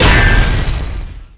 bladeslice5.wav